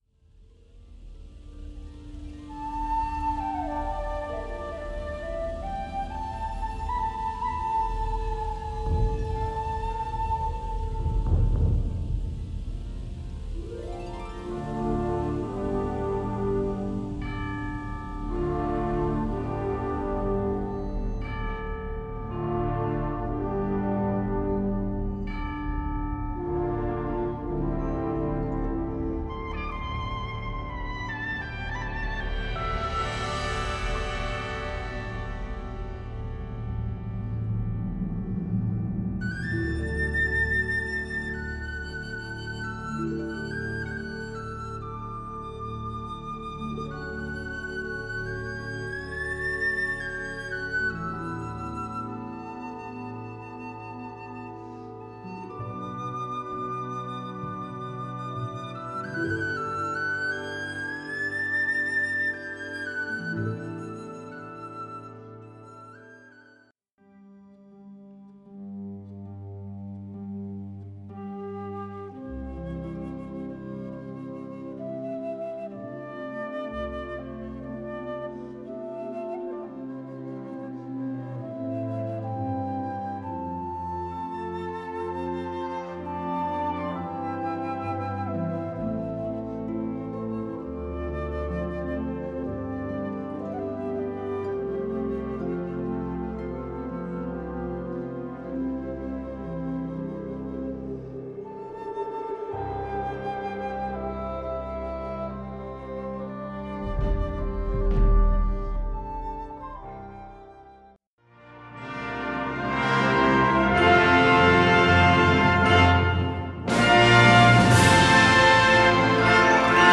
Besetzung: Blasorchester
Ein atemberaubendes Medley